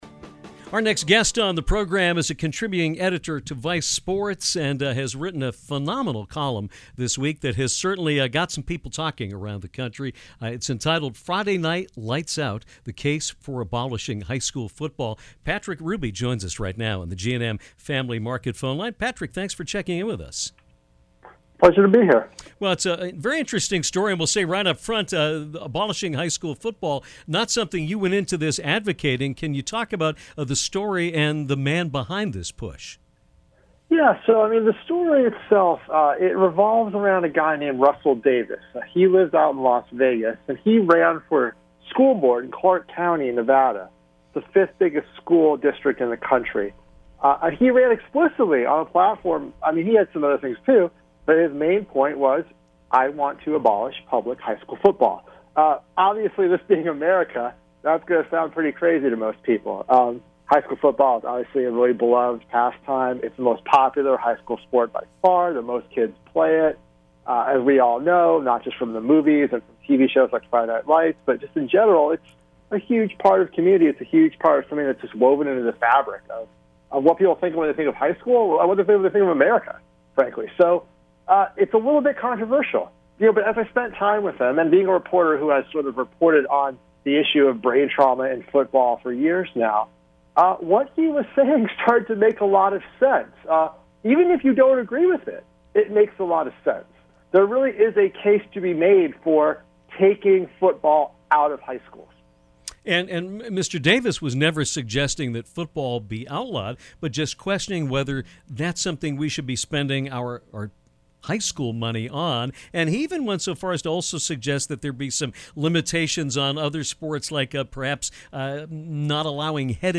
A school board candidate in Las Vegas proposed banning the sport in area schools and while his idea sounded far-fetched at first, there is plenty of research to indicate that he had some valid reasons. Check out this thought-provoking conversation from Friday’s show.